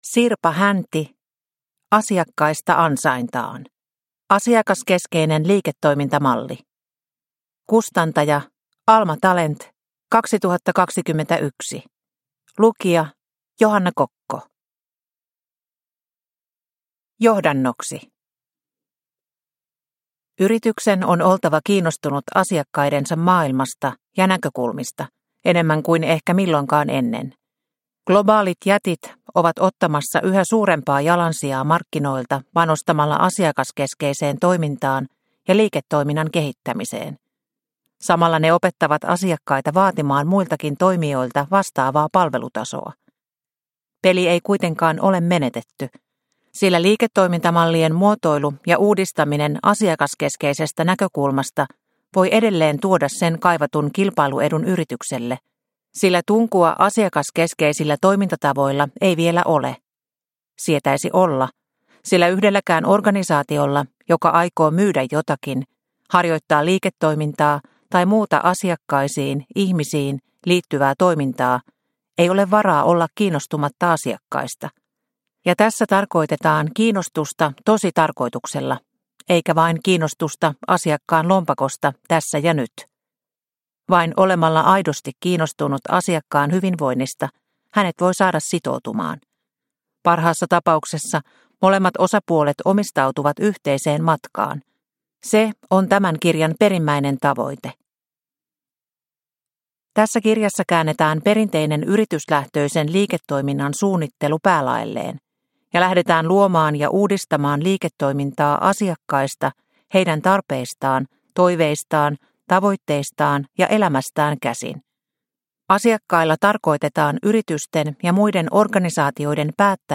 Asiakkaista ansaintaan – Ljudbok – Laddas ner